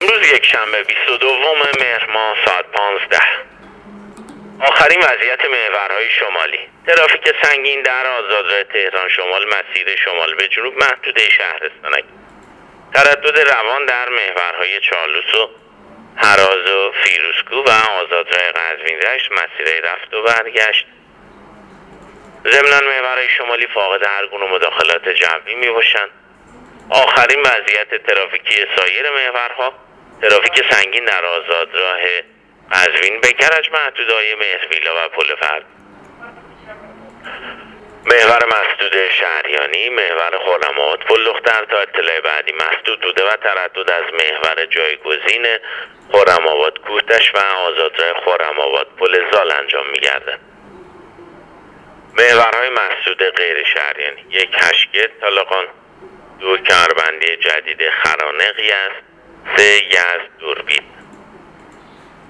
گزارش رادیو اینترنتی از آخرین وضعیت ترافیکی جاده‌ها تا ساعت 15 روز ۲۲ مهر